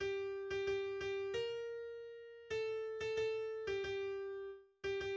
\header { tagline = "" } \language "deutsch" \layout { indent = #0 } akkorde = \chordmode { \germanChords % \partial 4 f4 c:7 f4. c8 f4 c:7 f2 c:7 f c:7 f c:7 f } melodie = \relative c'' { \time 4/4 \tempo 4 = 90 \key b \major \autoBeamOff % \partial 4 g8. g16 g8 g b4.. a8. a16 a8. g16 g4 r8 g16 g \bar "|."